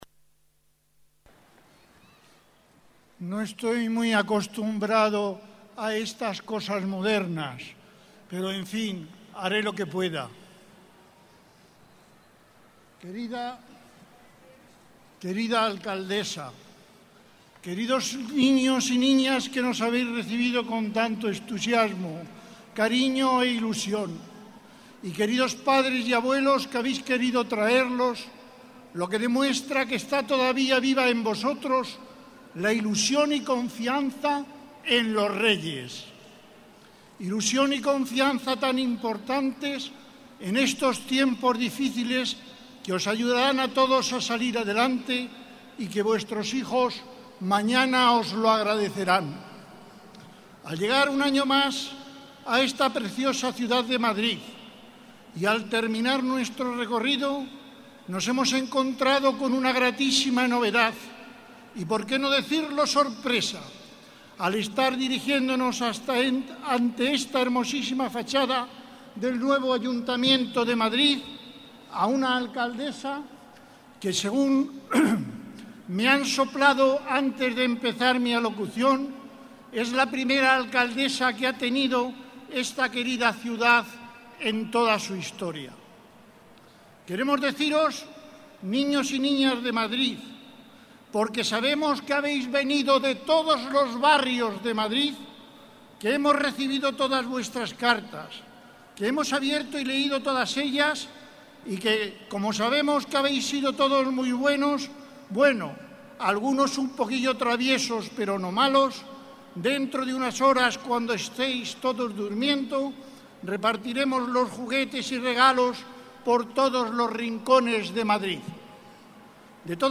Noche mágica en Cibeles - Ayuntamiento de Madrid
Nueva ventana:Palabras del Rey Melchor. Joaquín María Martínez, concejal de Villaverde